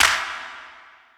Claps
CC - Decontaminated Clap.wav